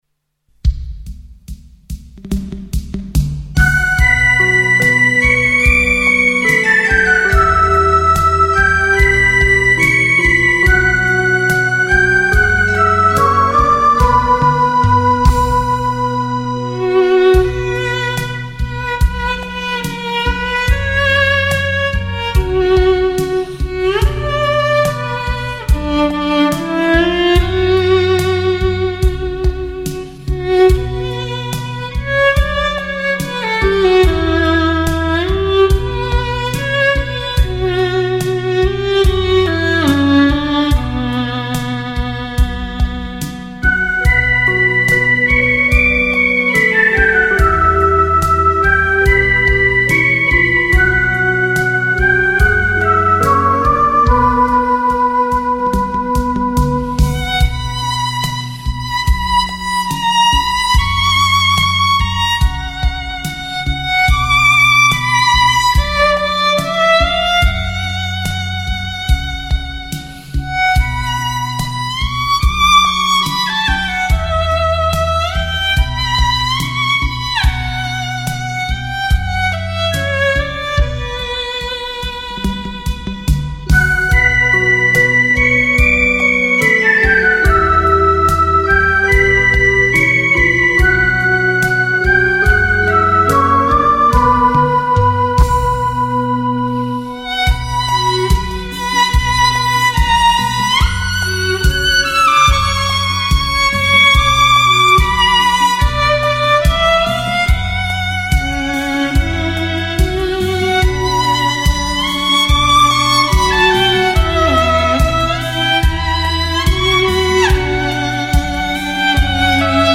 专辑英文名: violin & electronic organ
专辑格式：DTS-CD-5.1声道
俄罗斯民歌